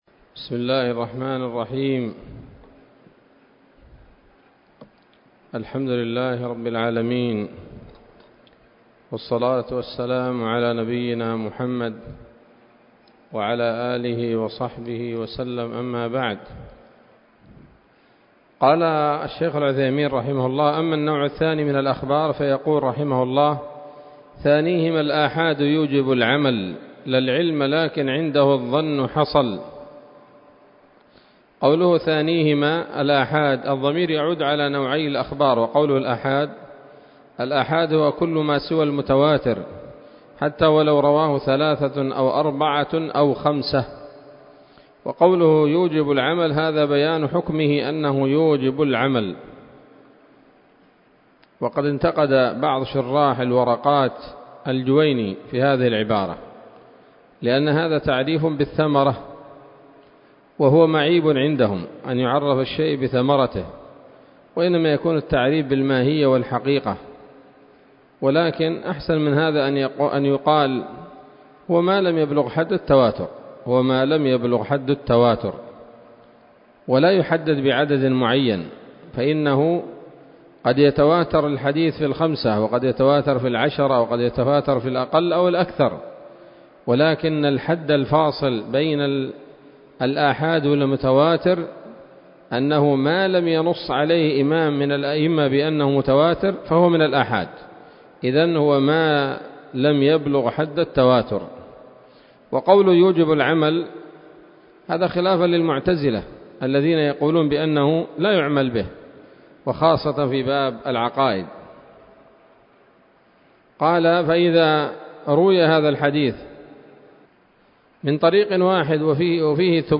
الدرس الثامن والخمسون من شرح نظم الورقات للعلامة العثيمين رحمه الله تعالى